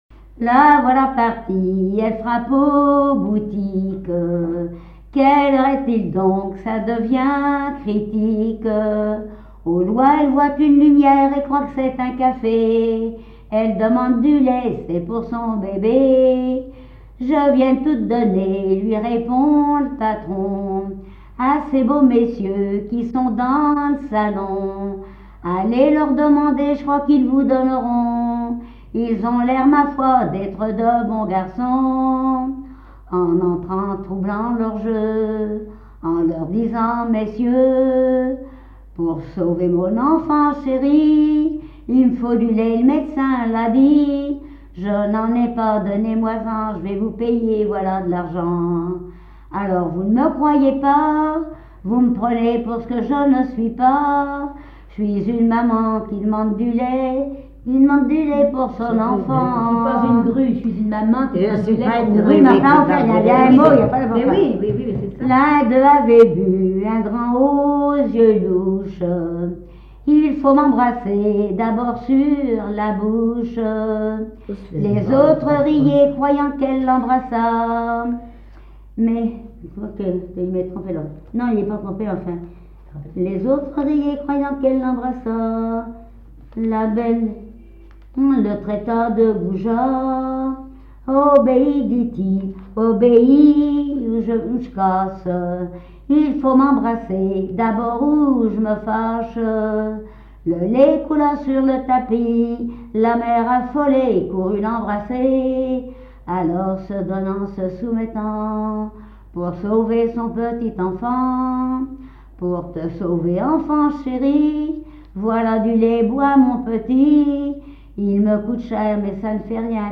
chanson du répertoire des conserverie
Genre strophique
Pièce musicale inédite